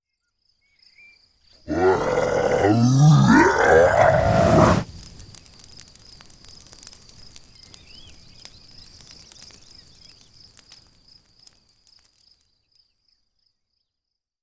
Sonidos: Especiales
Sonidos: Animales
Sonidos: Fx web